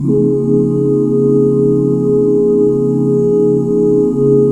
DBMAJ7 OOO-L.wav